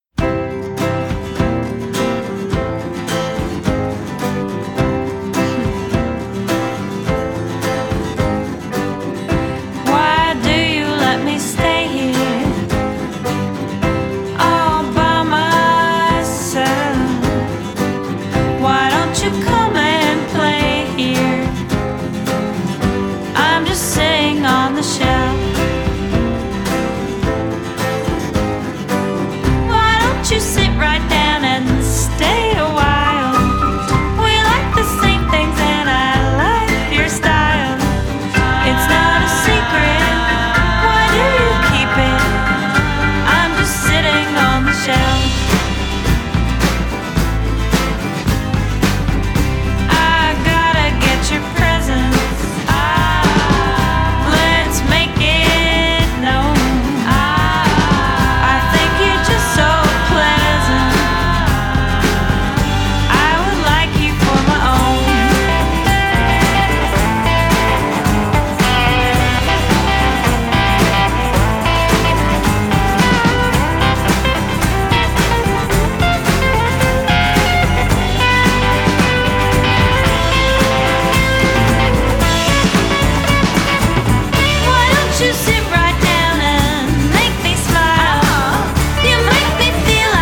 on a fun, kitschy yet highly melodic ride